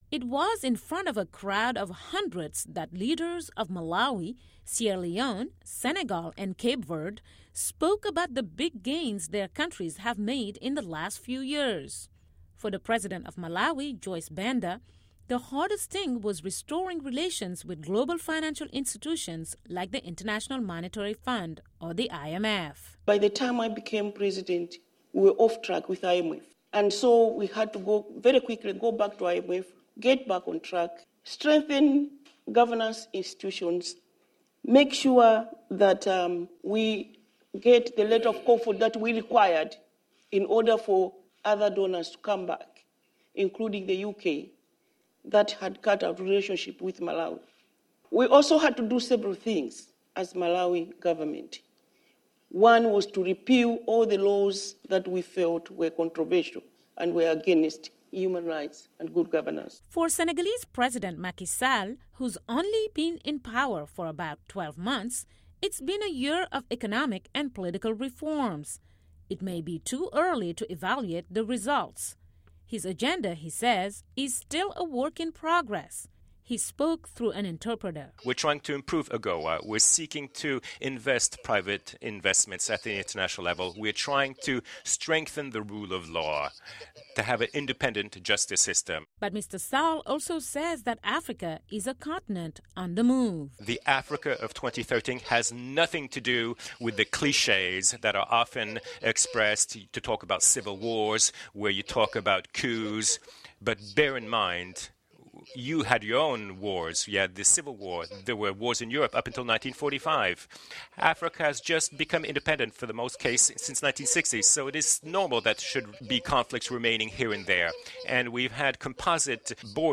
Listen to a report on consolidating democratic gains and promoting African prosperity